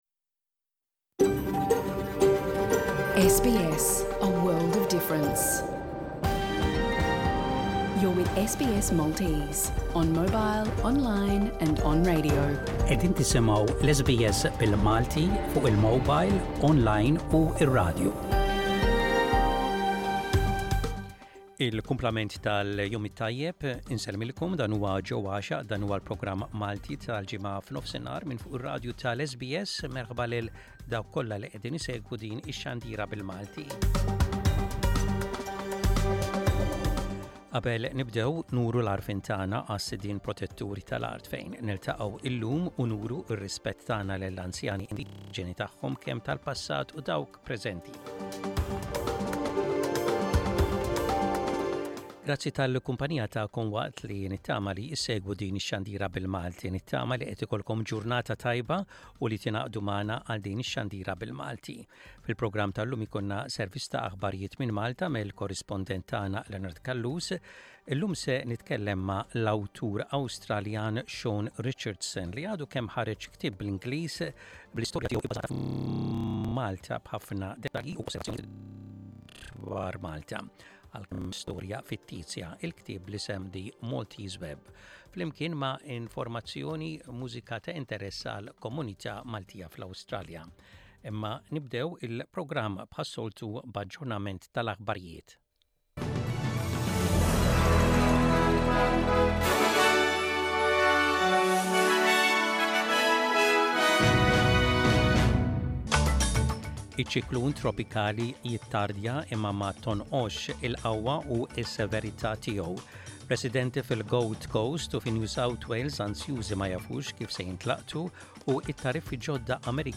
Jinkludi fost affarijiet oħra, l-aħbarijiet mill-Awstralja, servizz ta' aħbarijiet minn Malta mill-korrispondent